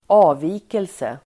Uttal: [²'a:vi:kelse]